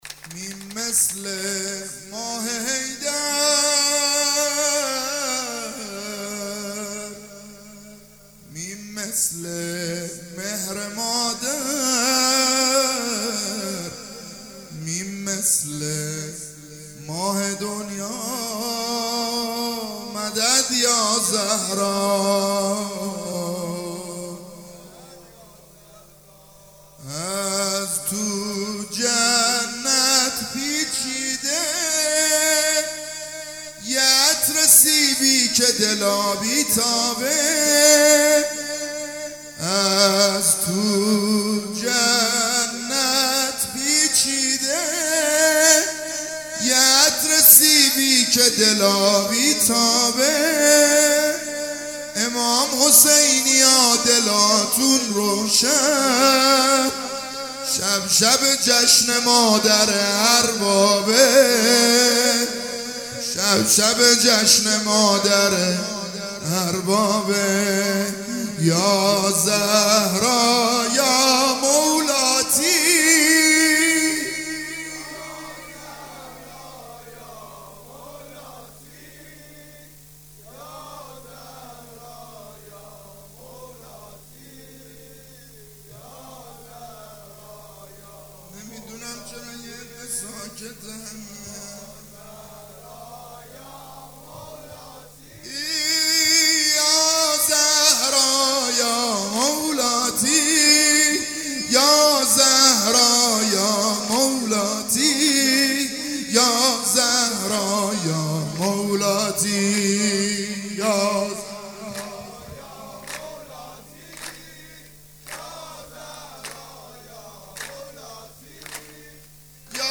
ولادت حضرت زهرا(س)